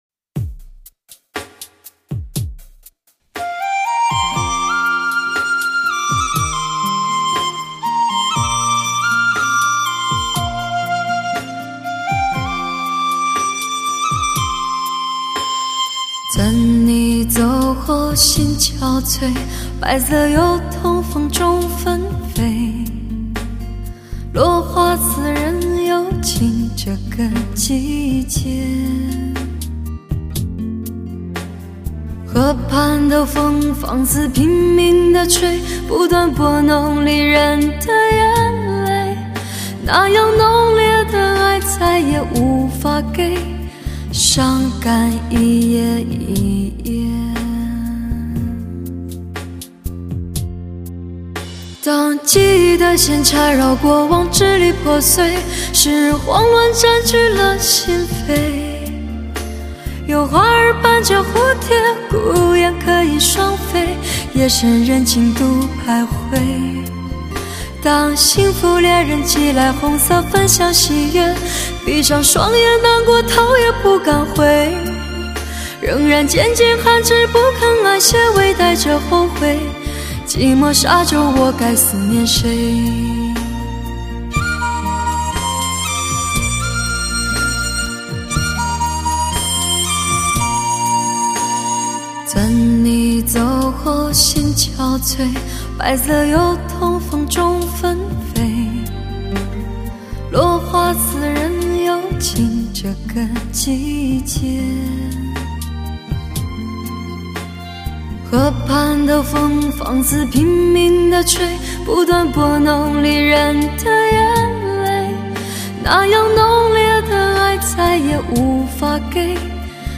的淡漠……歌者成熟的嗓音，及细腻而饱满的情感，很是让人动情。